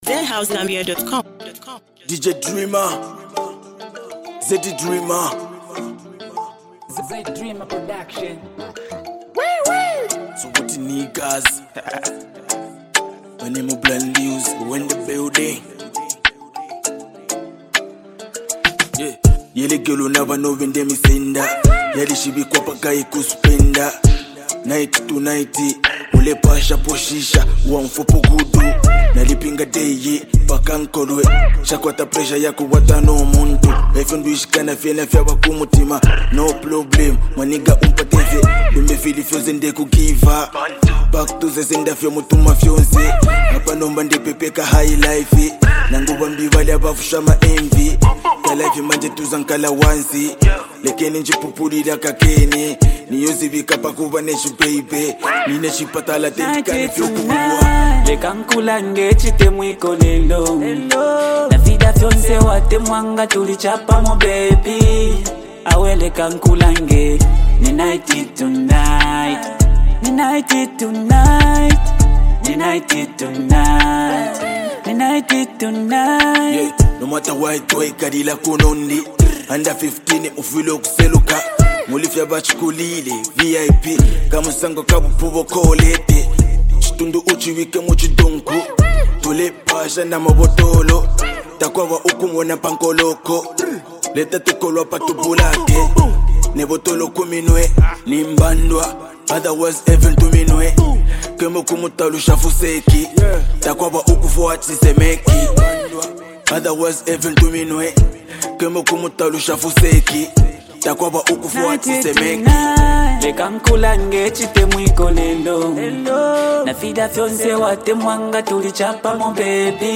smooth collaboration
blending powerful lyrics with a chill vibe.